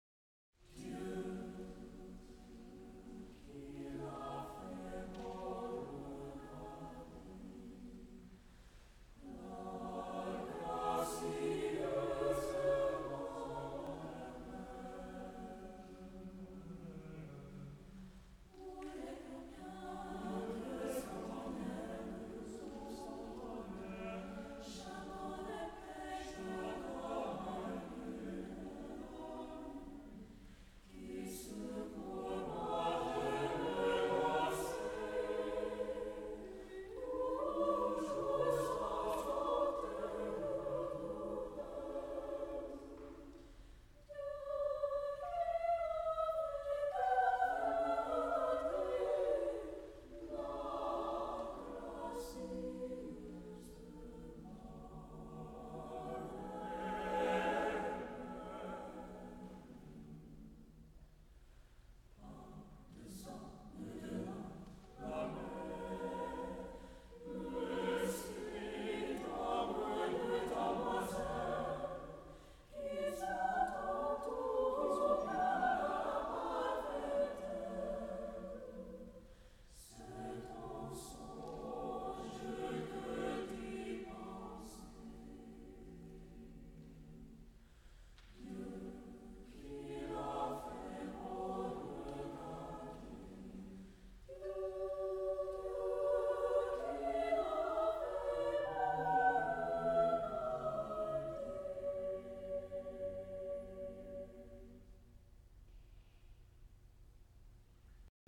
csucs - Music from the CSU Chamber Singers while I was a member
NCCO Inaugural Conference Closing Concer